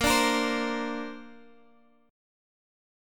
Bbadd9 chord